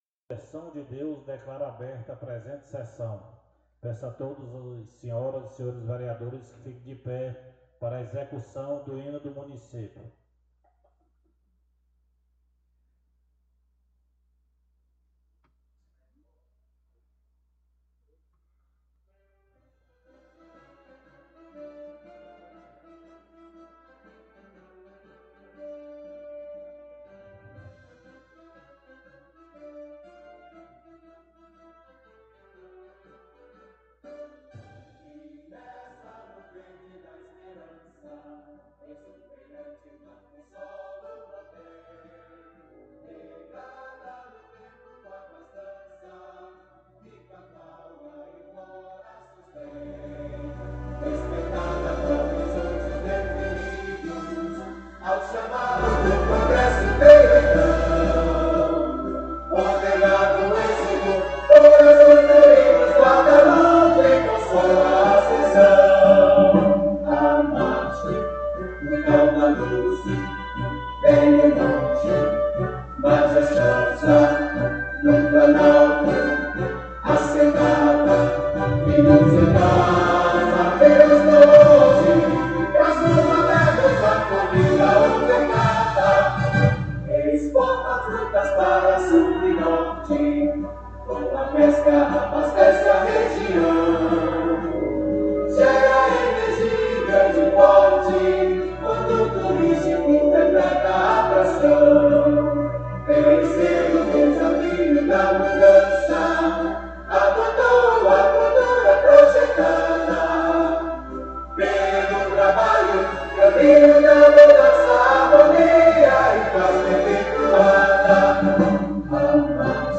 O Presidente Adão Moura, sob a proteção de Deus, declarou aberta a presente sessão. Executado o Hino de Guadalupe. Lida e aprovada a ata da sessão anterior sem ressalvas.